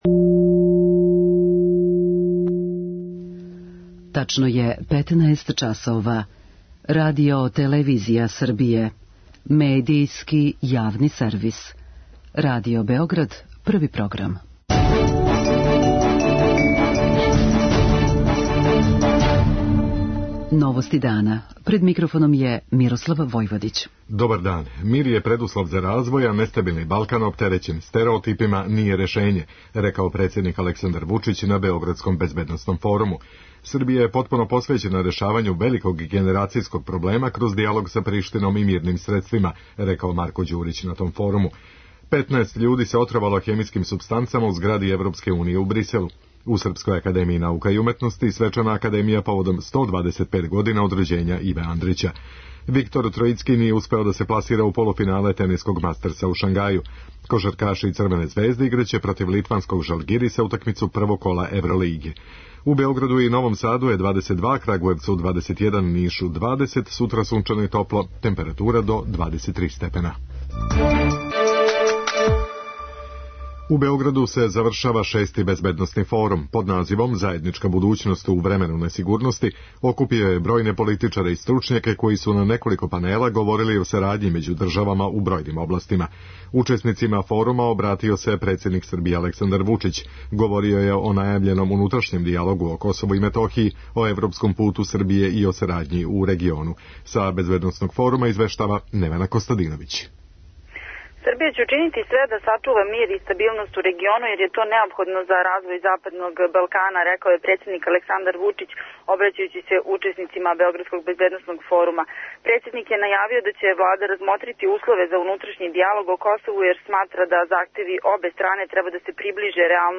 преузми : 5.50 MB Новости дана Autor: Радио Београд 1 Најпопуларнија радијска информативна емисија на нашим просторима деценијама уназад доноси најбрже и најсвеобухватније информације.